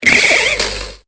Cri de Mygavolt dans Pokémon Épée et Bouclier.